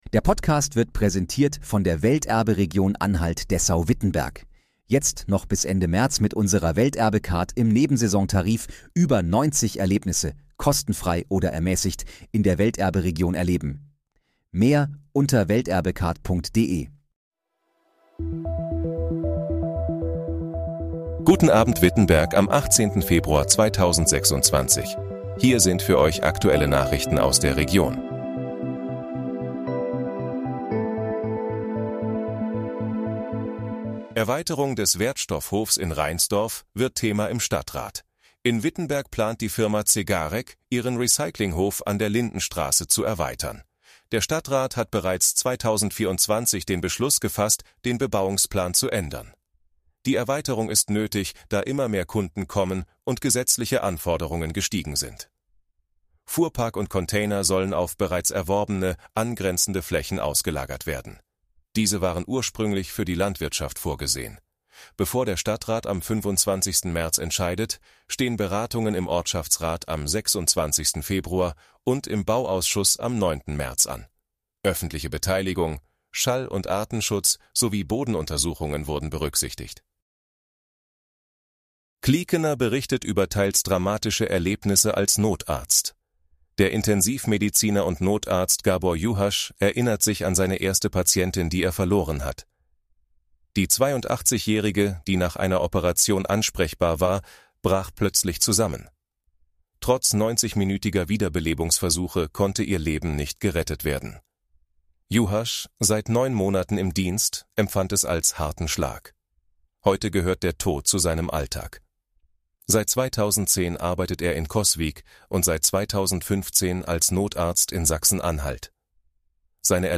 Guten Abend, Wittenberg: Aktuelle Nachrichten vom 18.02.2026, erstellt mit KI-Unterstützung
Nachrichten